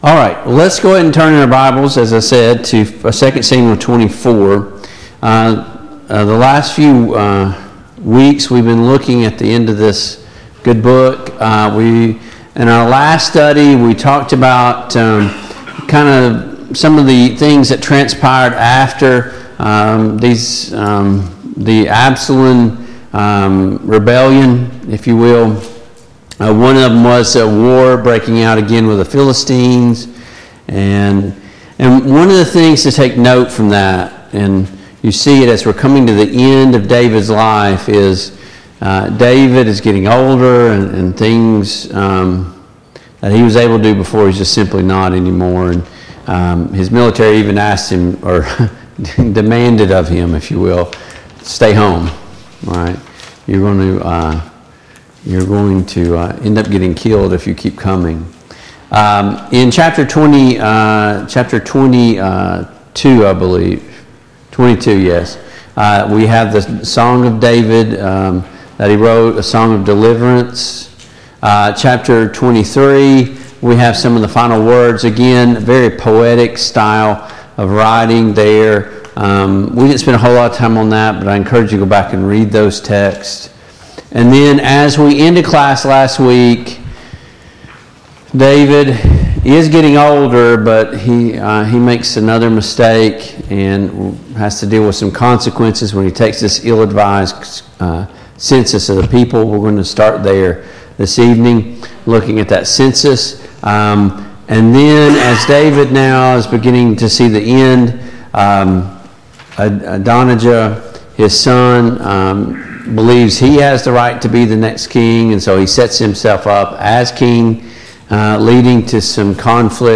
The Kings of Israel Passage: 2 Samuel 24 Service Type: Mid-Week Bible Study Download Files Notes Topics